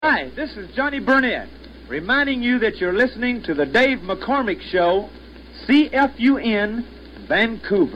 Johnny Burnette plugs